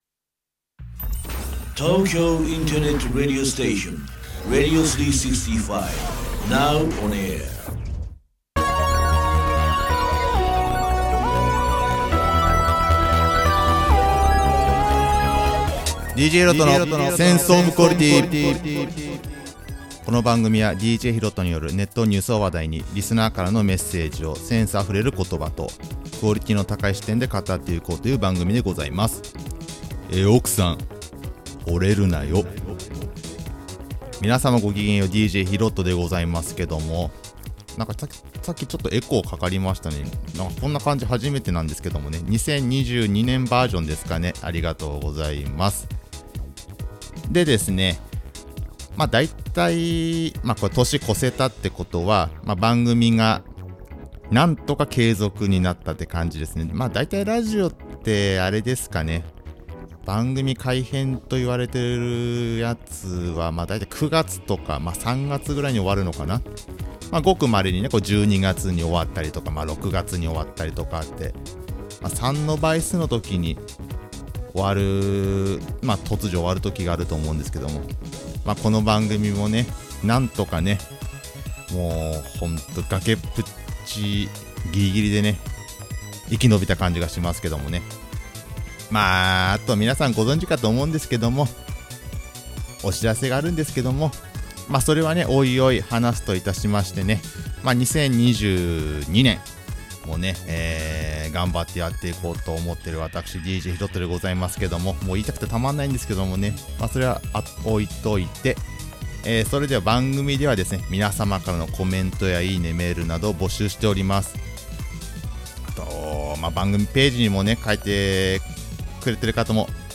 テンションちょい上げで収録しました！